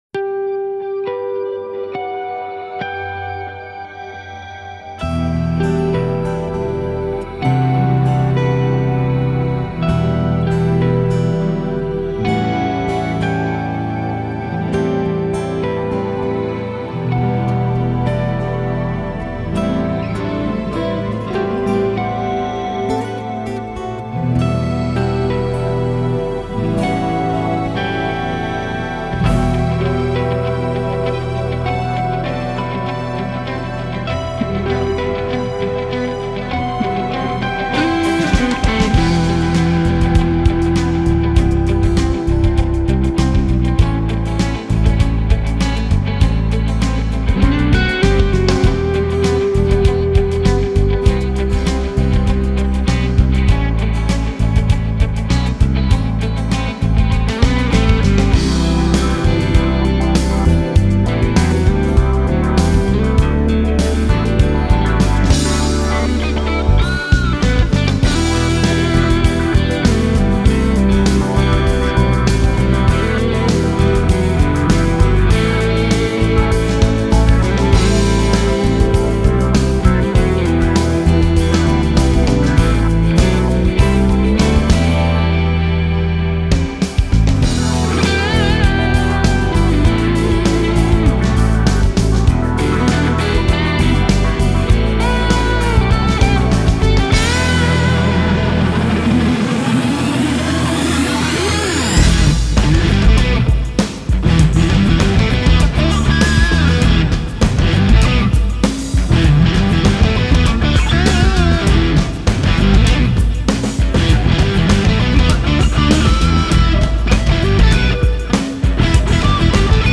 rockig, positiv
Tempo 99 Em